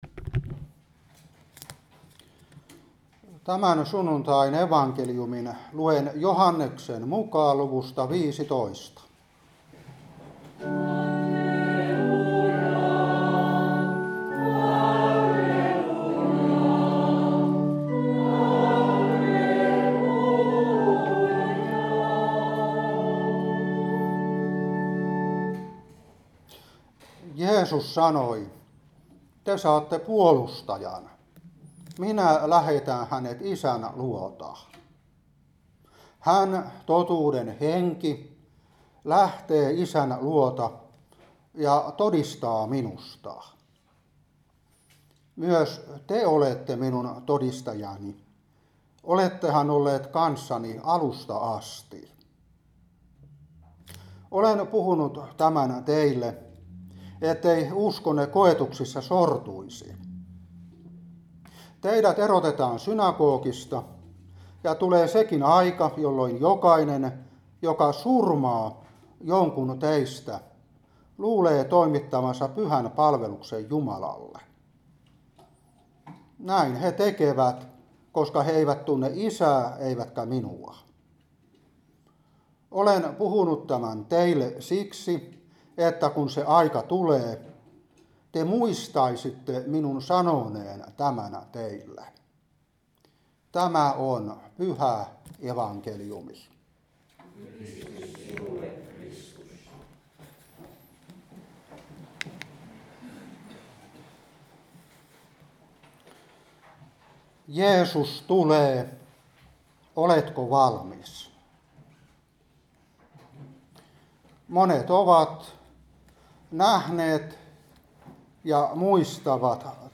Saarna 2025-5. Joh.15:26-16:4.